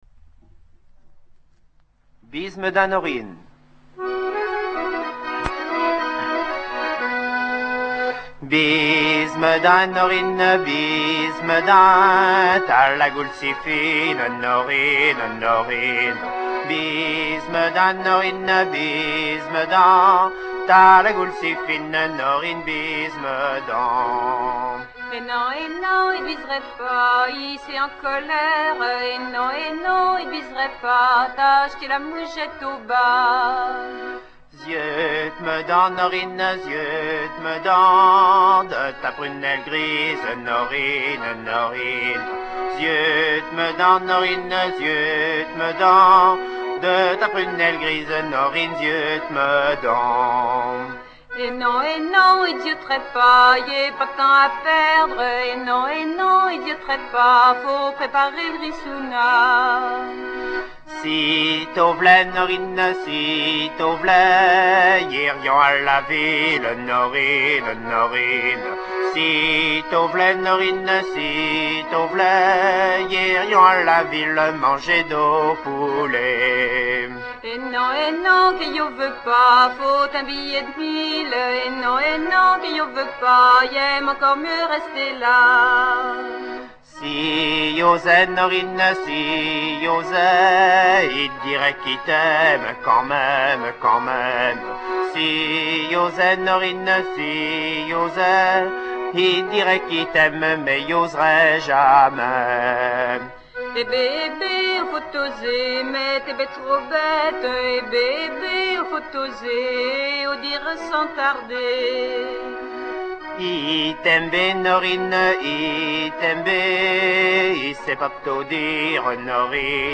Bise me din Norine (chanson)
beaucoup plus calme